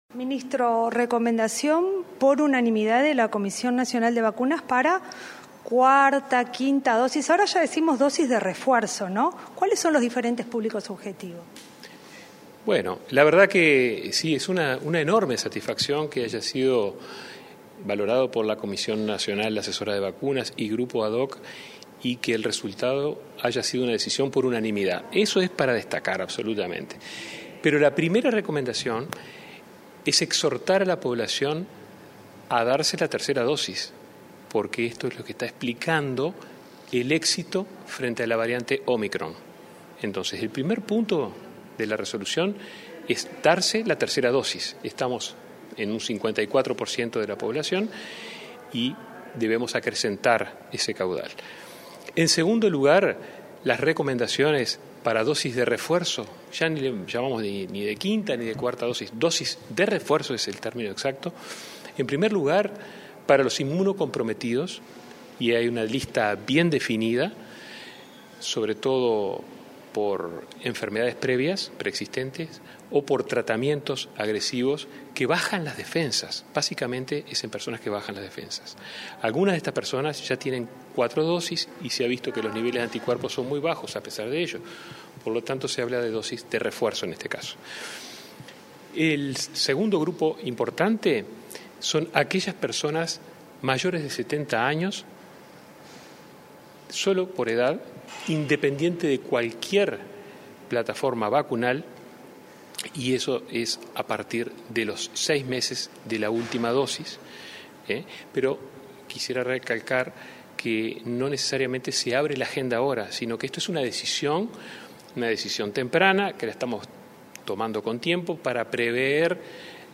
Entrevista al ministro de Salud Pública, Daniel Salinas